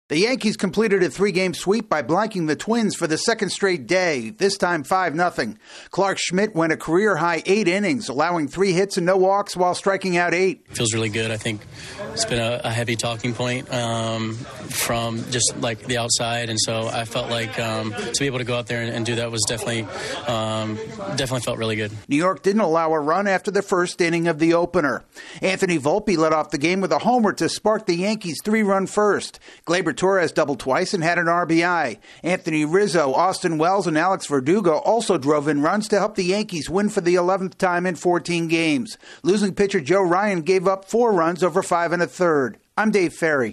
The Yankees run their scoreless streak to 26 innings. AP correspondent